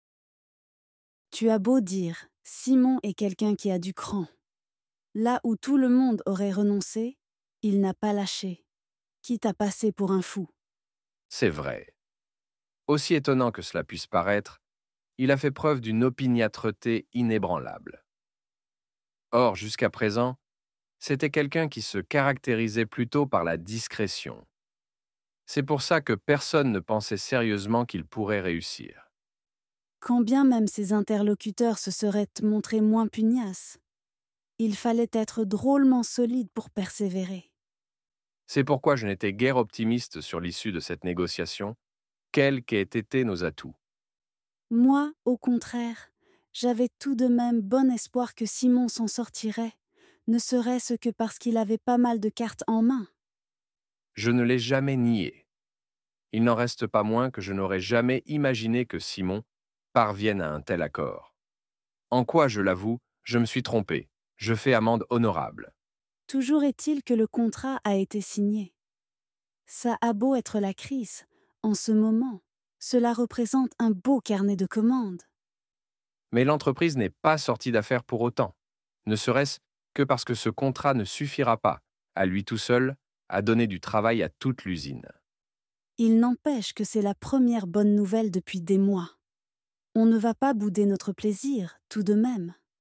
Dialogue en français niveau B2 négociation
Ce dialogue en français niveau B2 négociation met en scène une conversation stratégique entre Annabelle et Laurent autour des compétences d’un négociateur.